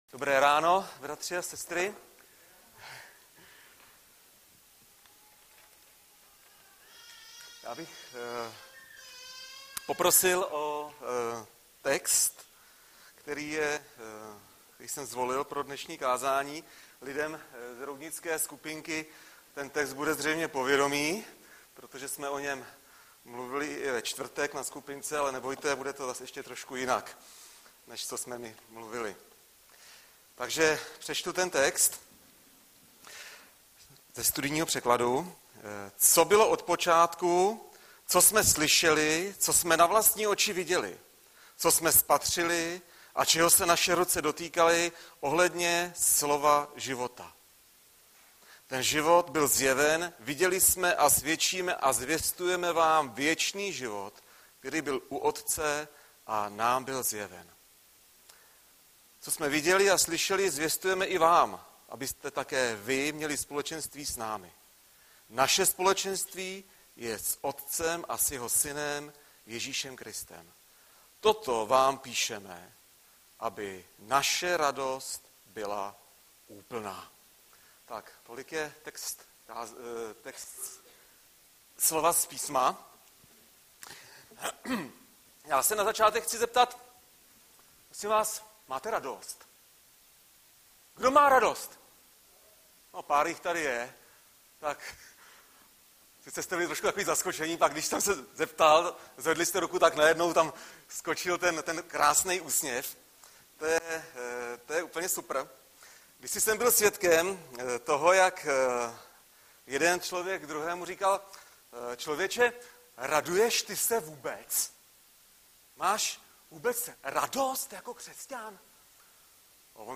Webové stránky Sboru Bratrské jednoty v Litoměřicích.
Audiozáznam kázání si můžete také uložit do PC na tomto odkazu.